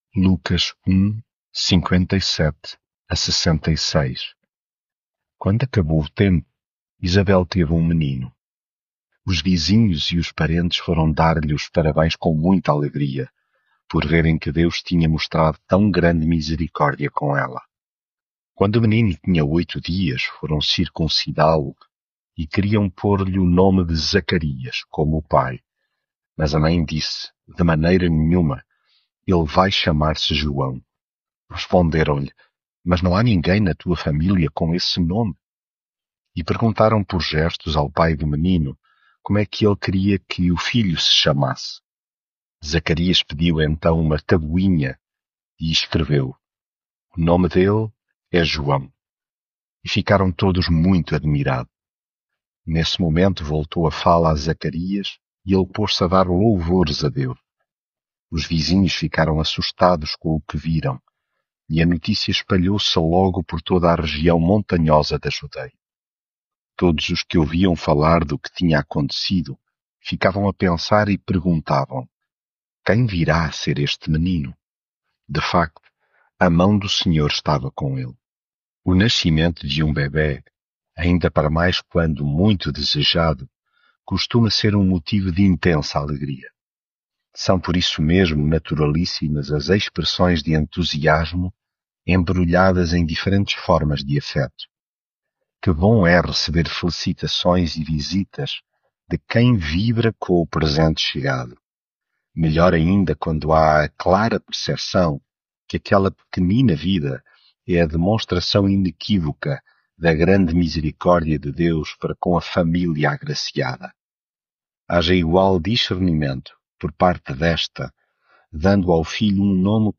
devocional Lucas leitura bíblica Quando acabou o tempo, Isabel teve um menino.
Devocional